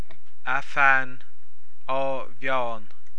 Audio File (.wav) Comhad Fuaime Foghraíochta